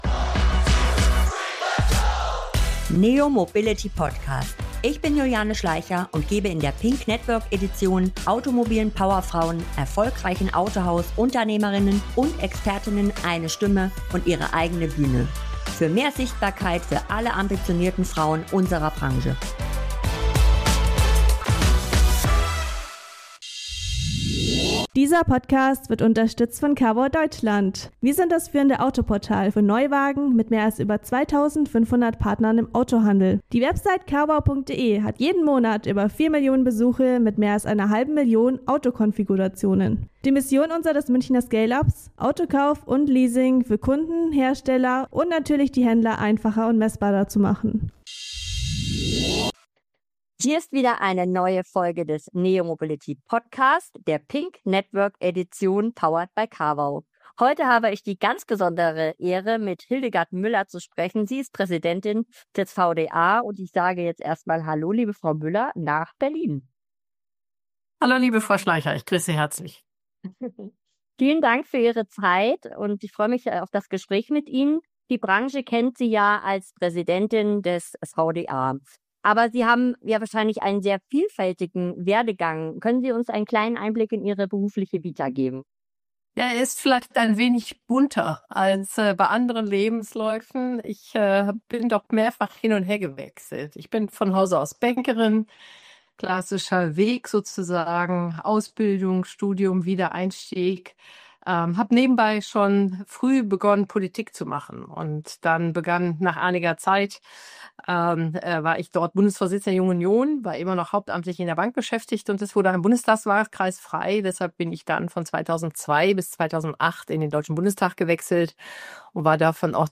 Als Präsidentin des größten deutschen Autoindustrie-Verbandes ist Hildegard eine der sichtbarsten Frauen unserer Branche. Doch wer ist die Frau hinter dem Amt? Ein Gespräch mit einem Familienmenschen, einer Krimi-Leserin und FC Bayern-Fan!